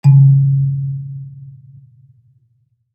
kalimba_bass-C#2-ff.wav